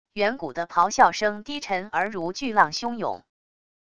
远古的咆哮声低沉而如巨浪汹涌wav音频